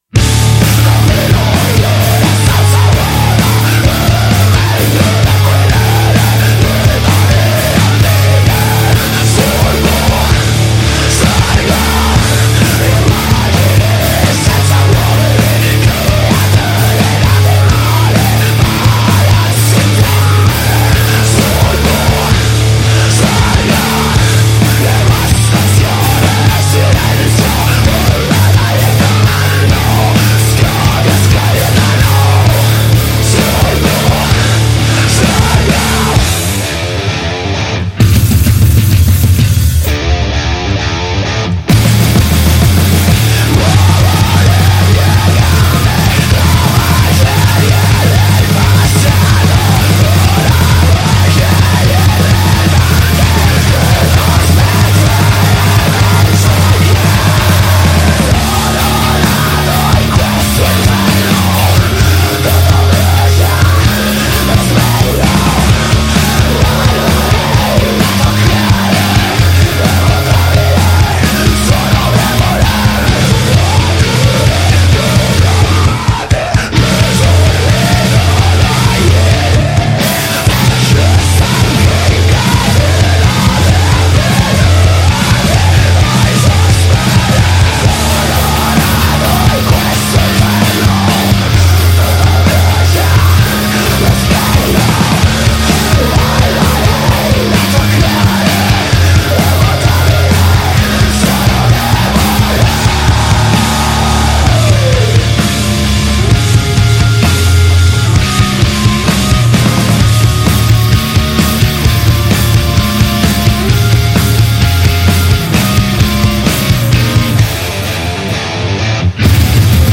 Intervista a I Maiali | Punkontherocks 27-6-22 | Radio Città Aperta